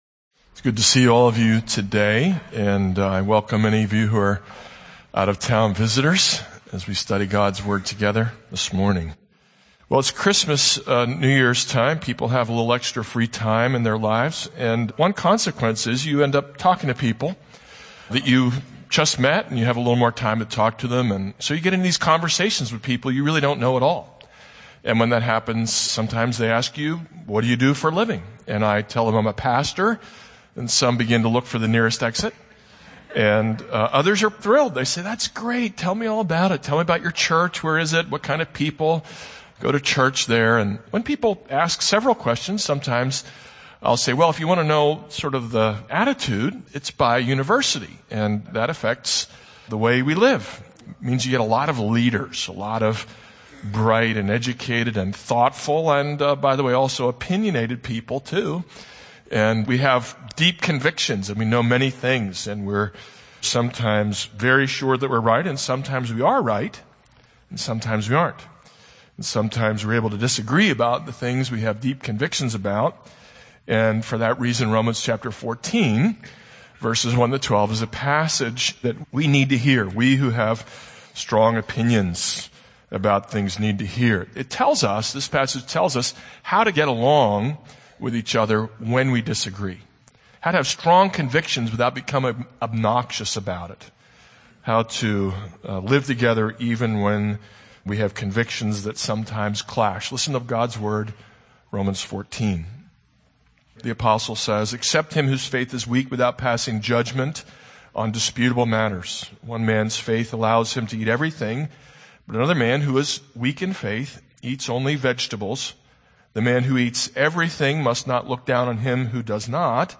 This is a sermon on Romans 14:1-12.